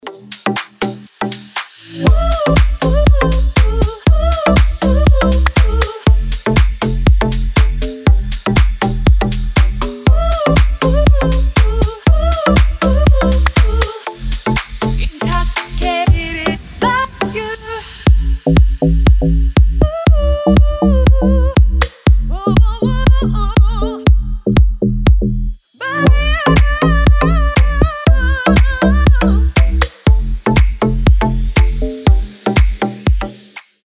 Красивая клубная мелодия с затяжным вокалом Ура!